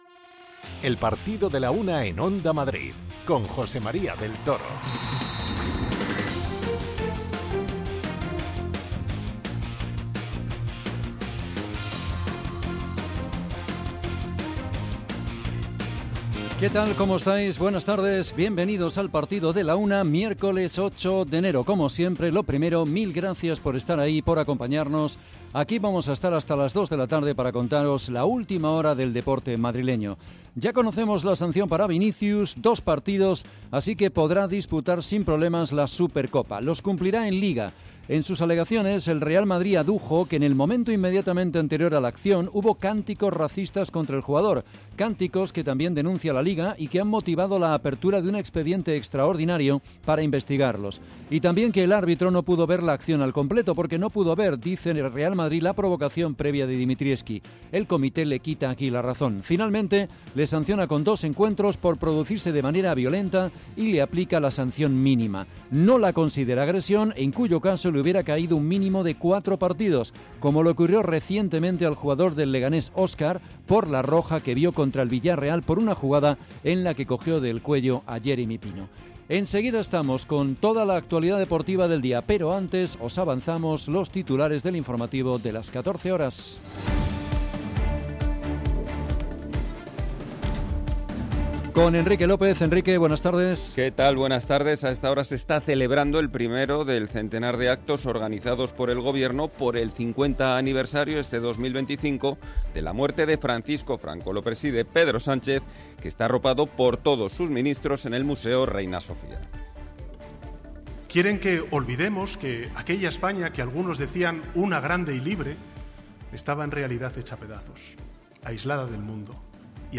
Día especial en el que contamos en directo el sorteo de los octavos de final de la Copa del Rey con las primeras reacciones. Pontevedra-Getafe, Almería-Leganés, Real Madrid-Celta, Elche-Atlético Madrid y Real Sociedad-Rayo Vallecano, esos han sido los emparejamientos de los nuestros.